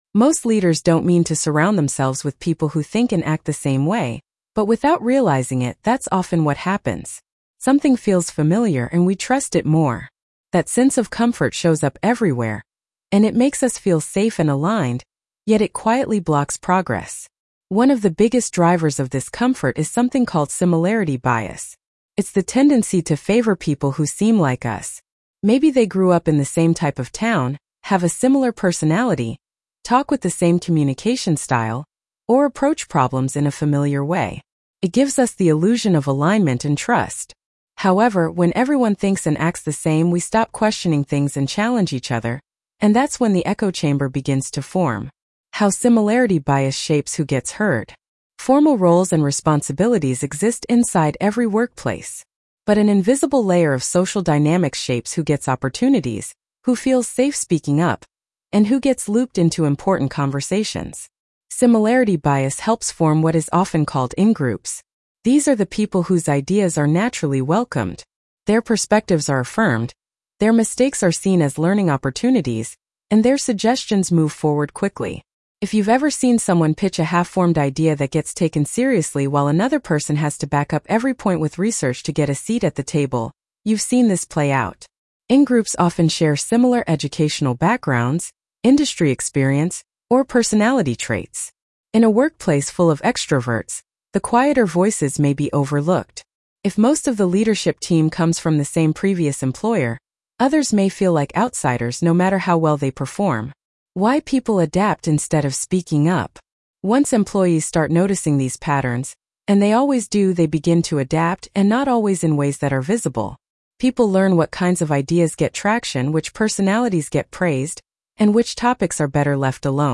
Breaking the Echo Chamber Blog Narration.mp3